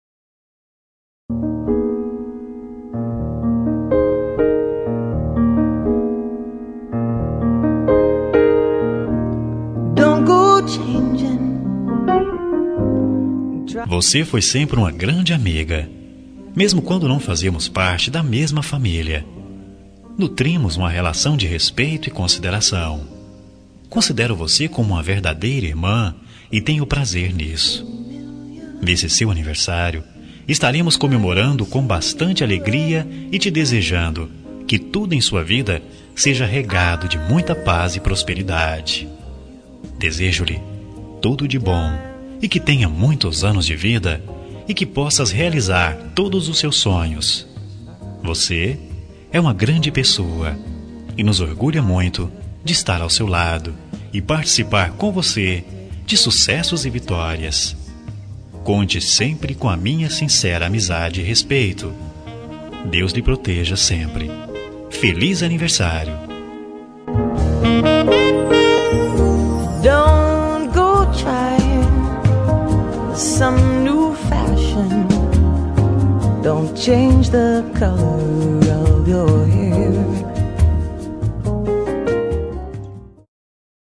Aniversário de Cunhada – Voz Masculino – Cód: 2646